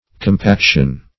Compaction \Com*pac"tion\, n. [L. compactio.]